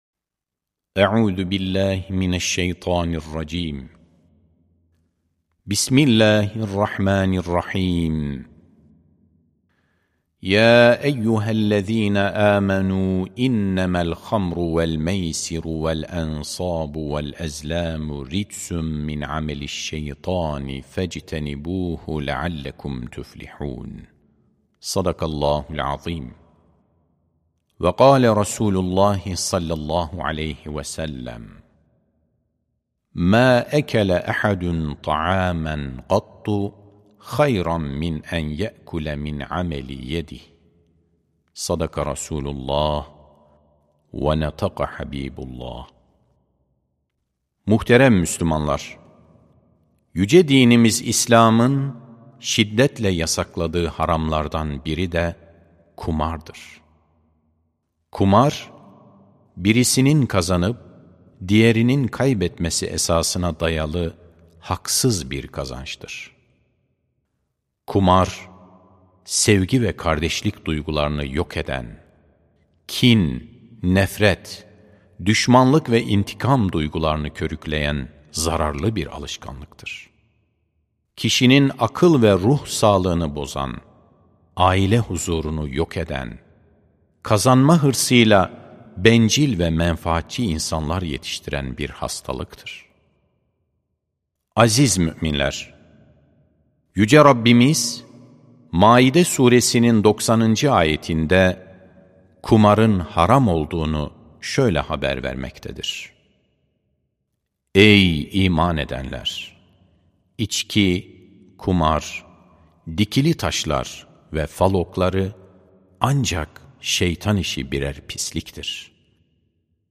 Sesli Hutbe